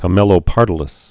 (kə-mĕlō-pärdl-ĭs)